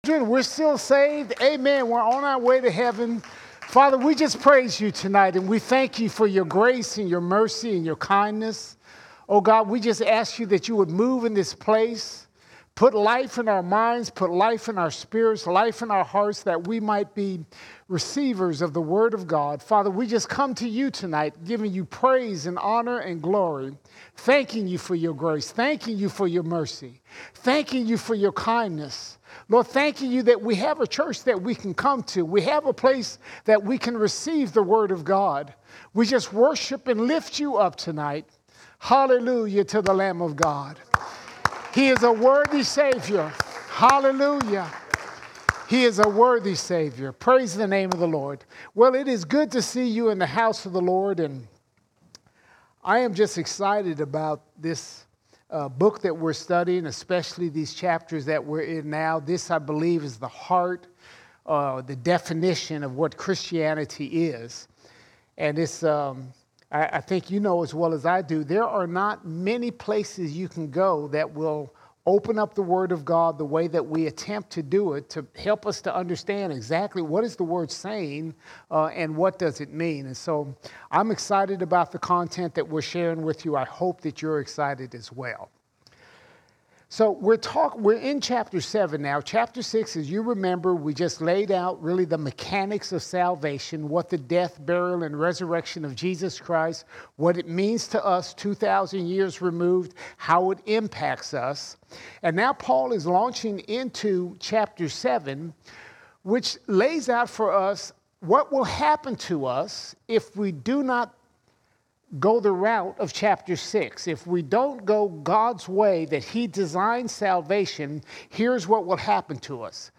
7 September 2023 Series: Romans All Sermons Roman 7:3 to 7:10 Roman 7:3 to 7:10 We have a sin nature that we fight against the wrong way - by going back under the Law or we make up our own laws.